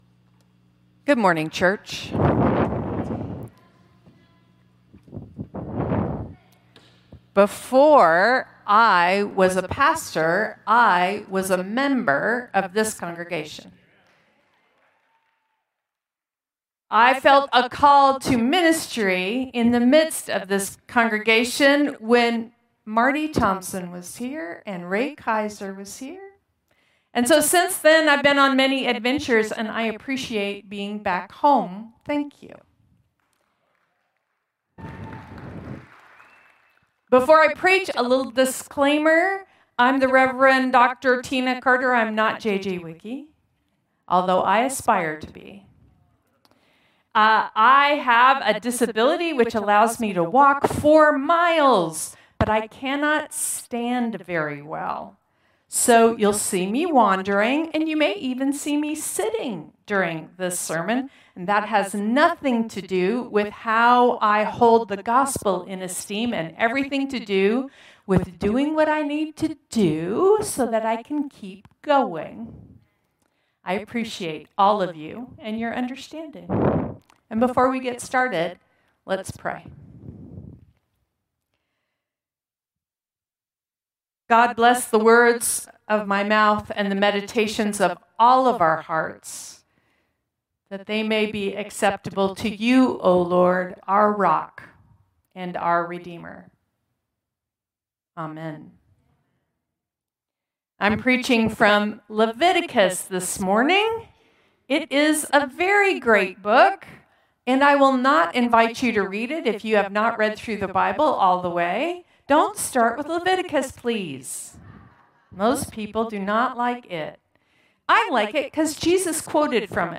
Contemporary Service 8/3/2025